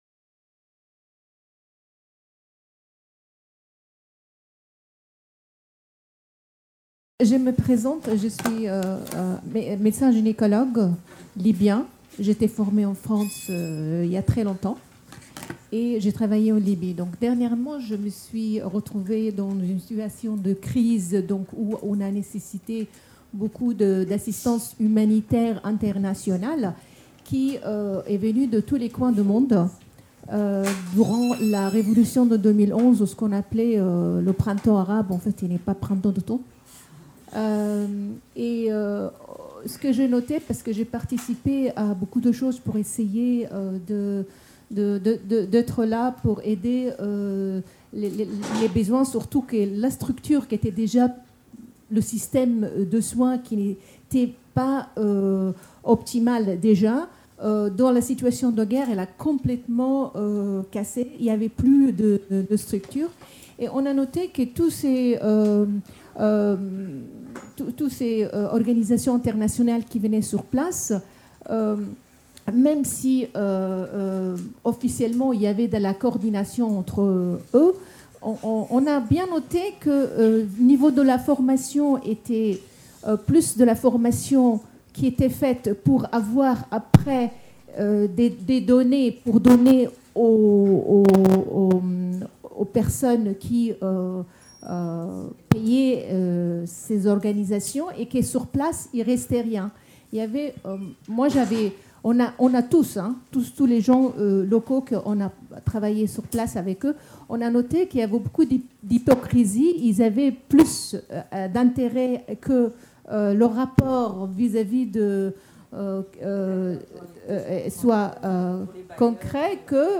5ème Journée Humanitaire sur la Santé des Femmes dans le Monde - TABLE RONDE : De nouveaux outils dans la réduction de la mortalité materno-infantile.